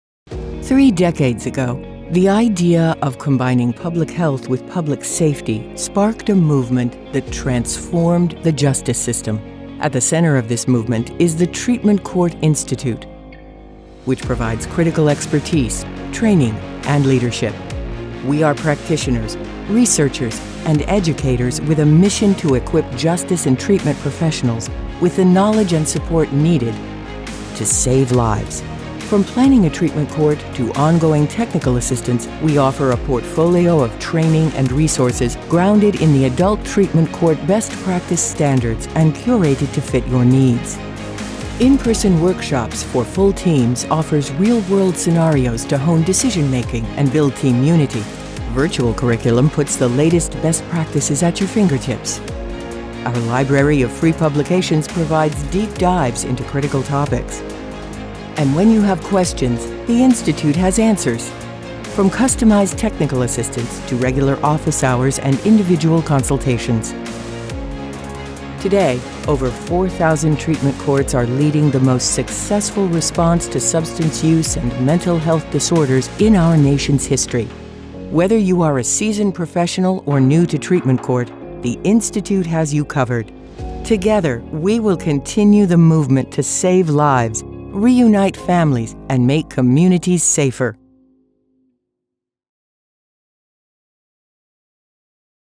I'm a experienced professional with a voice that is rich, worm and slightly breathy
Middle Aged